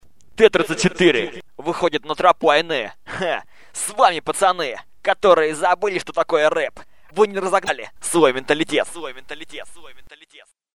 Скит